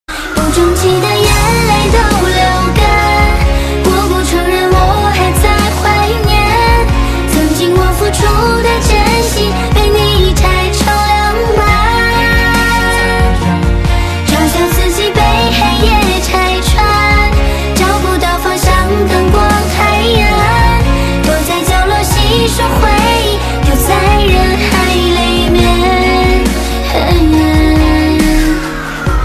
M4R铃声, MP3铃声, 华语歌曲 112 首发日期：2018-05-14 22:33 星期一